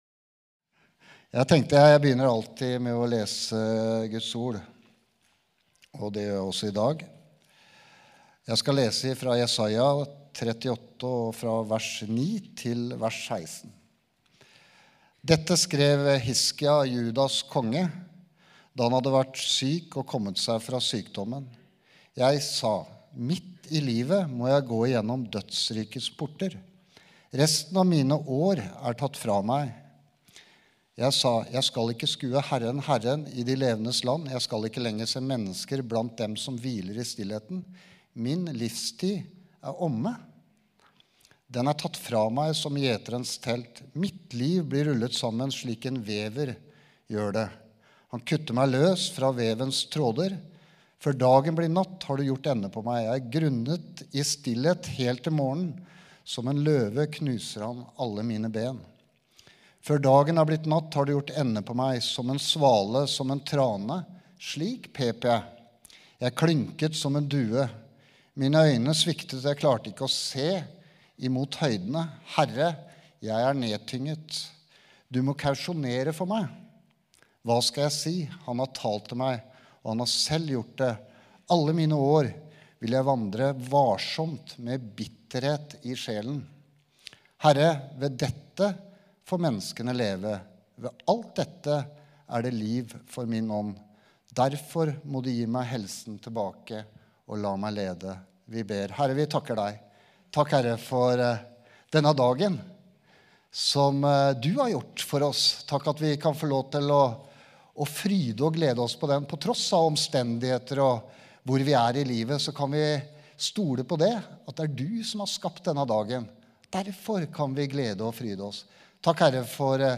Gudstjeneste